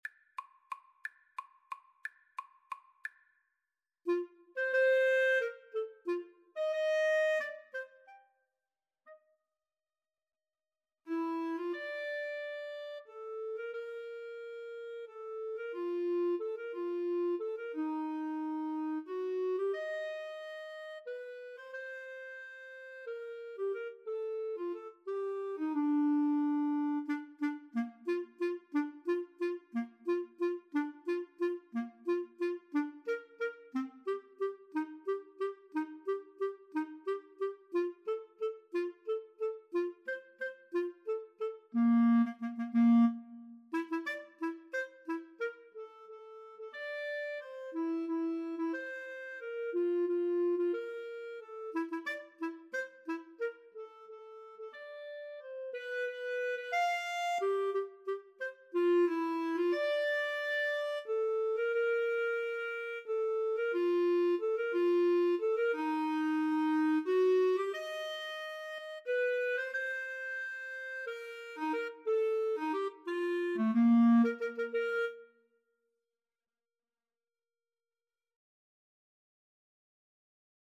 Bb major (Sounding Pitch) C major (Clarinet in Bb) (View more Bb major Music for Clarinet Duet )
3/4 (View more 3/4 Music)
~ = 180 Tempo di Valse
Clarinet Duet  (View more Intermediate Clarinet Duet Music)
Classical (View more Classical Clarinet Duet Music)